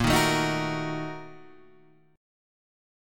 BbM7sus4#5 chord